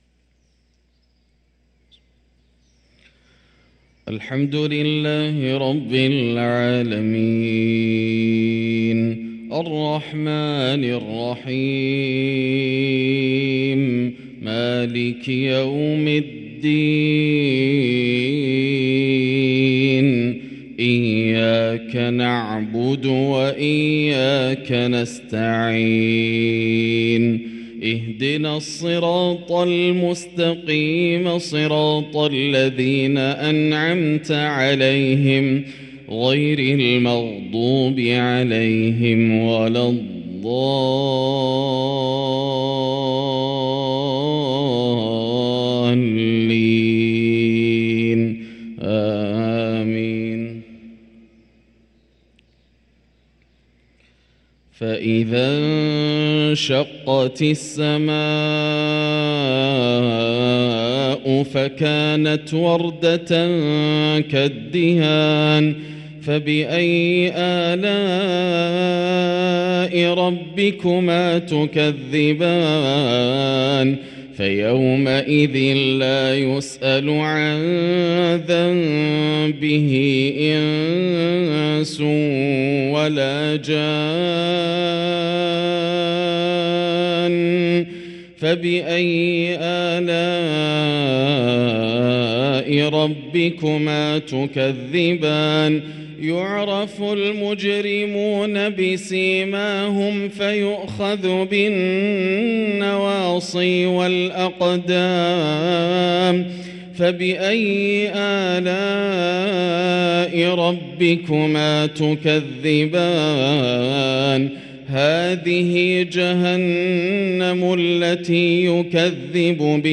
صلاة الفجر للقارئ ياسر الدوسري 21 شعبان 1444 هـ
تِلَاوَات الْحَرَمَيْن .